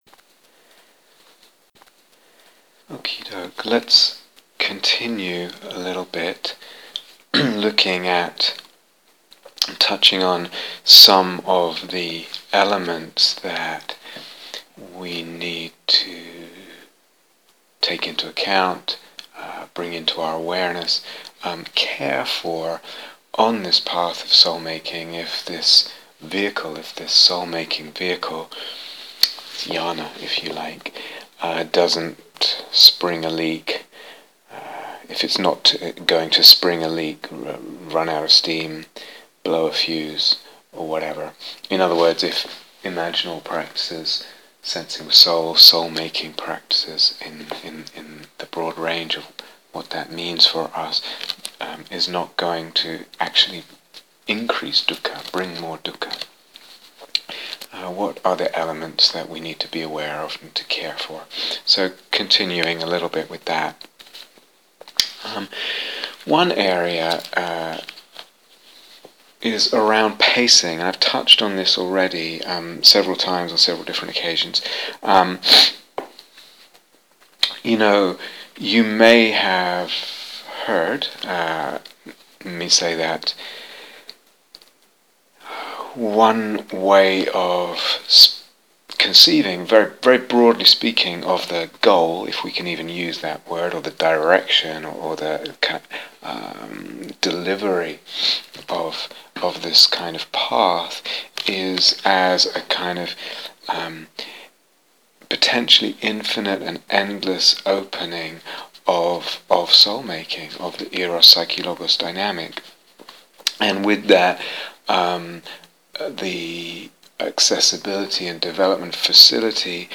talks (recorded
from his home